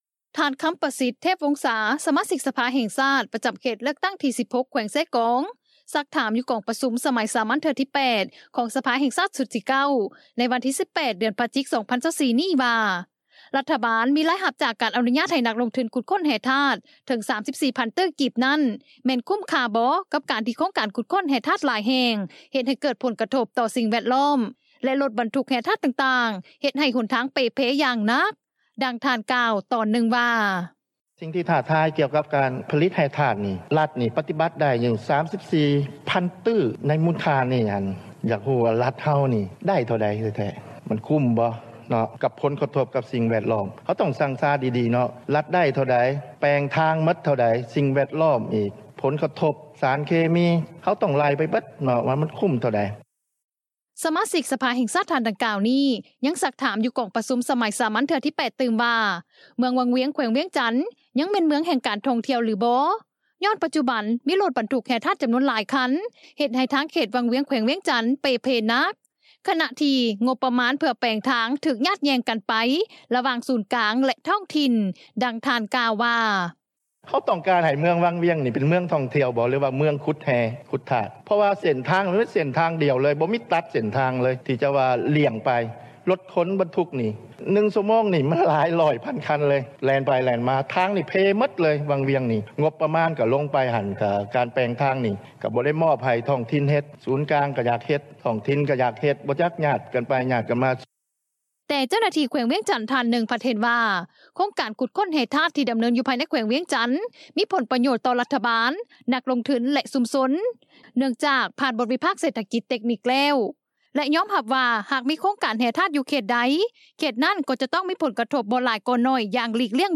ທ່ານ ຄໍາປະສິດ ເທບວົງສາ, ສະມາຊິກສະພາແຫ່ງຊາດ ປະຈໍາເຂດເລືອກຕັ້ງທີ 16 ແຂວງເຊກອງ ຊັກຖາມ ຢູ່ກອງປະຊຸມ ສະໄໝສາມັນ ເທື່ອທີ 8 ຂອງສະພາແຫ່ງຊາດ ຊຸດທີ 9 ໃນວັນທີ 18 ເດືອນພະຈິກ 2024 ນີ້ວ່າ ລັດຖະບານ ມີລາຍຮັບຈາກການອະນຸຍາດ ໃຫ້ນັກລົງທຶນຂຸດຄົ້ນແຮ່ທາດ ເຖິງ 34 ພັນຕື້ກີບນັ້ນ ແມ່ນຄຸ້ມຄ່າບໍ່ ກັບການທີ່ ໂຄງການຂຸດຄົ້ນແຮ່ທາດຫຼາຍແຫ່ງ ເຮັດໃຫ້ເກີດຜົນກະທົບ ຕໍ່ສິ່ງແວດລ້ອມ ແລະລົດບັນທຸກແຮ່ທາດຕ່າງໆ ເຮັດໃຫ້ຫົນທາງເປ່ເພຢ່າງໜັກ, ດັ່ງທ່ານກ່າວຕອນໜຶ່ງວ່າ: